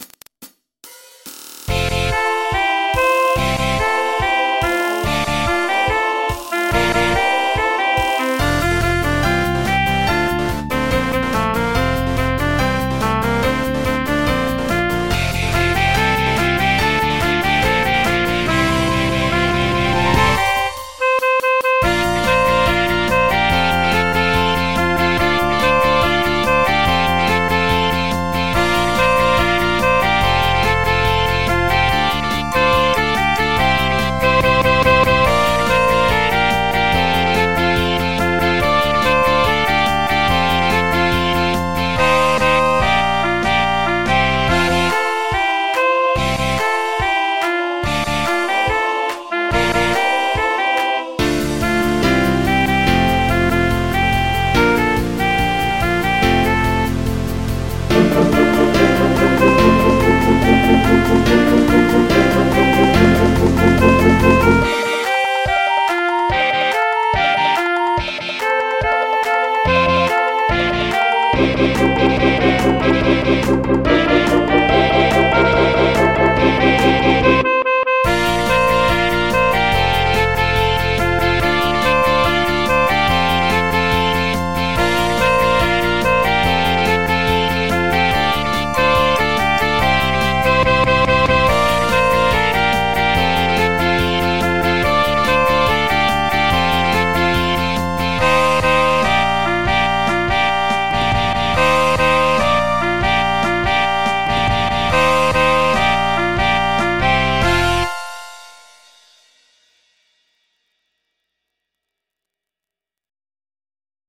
MIDI 72.01 KB MP3 (Converted) 1.74 MB MIDI-XML Sheet Music